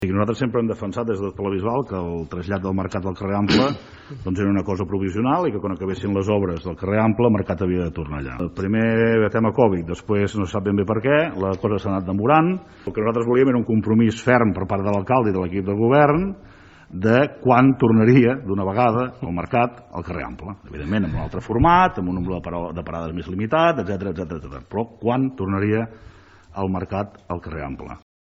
És per això que, en l’últim ple, el grup municipal Tots x la Bisbal va demanar que l’alcalde assegurés el retorn del mercat a la seva ubicació clàssica. Així ho explicava Xavier Dilmé, regidor de Tots x la Bisbal.